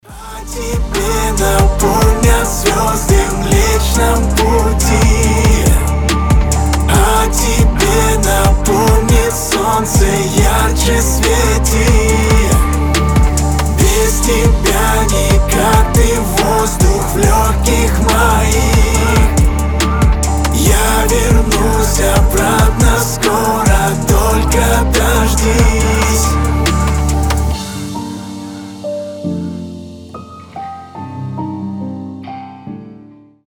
• Качество: 320, Stereo
лирика
мелодичные